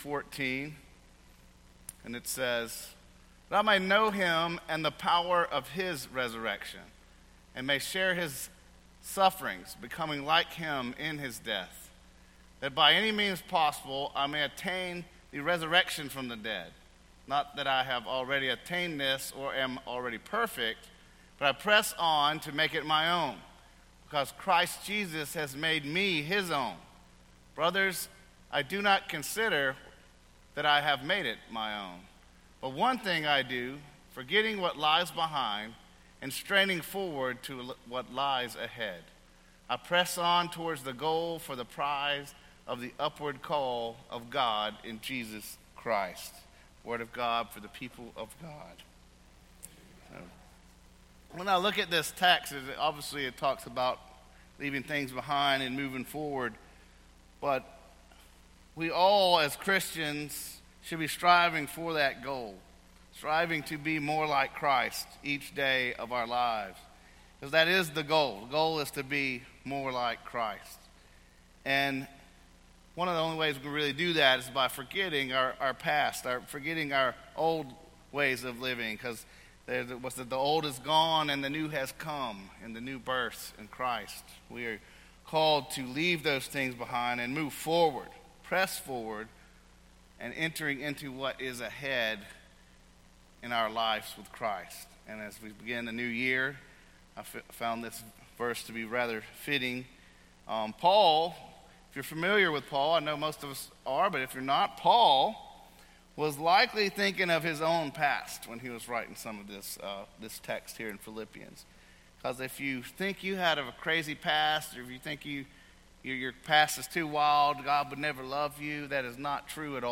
A message from the series "Uncategorized."